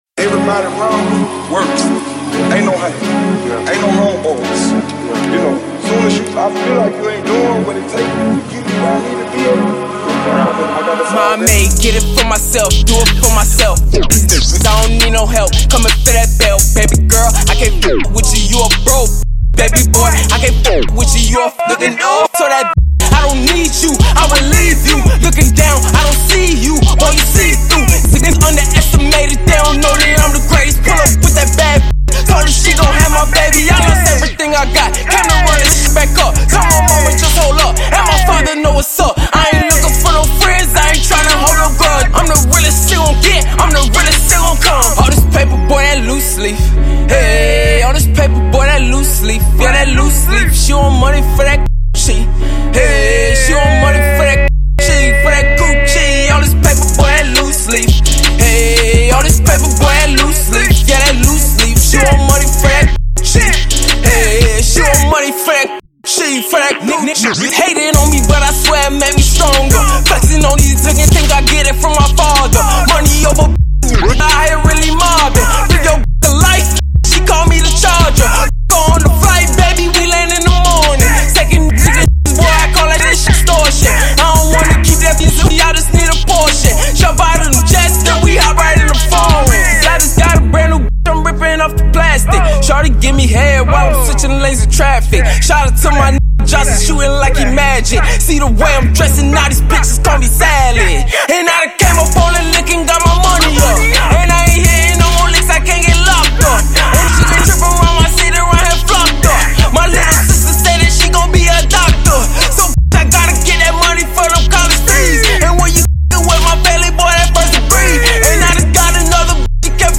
Ft. Lauderdale rapper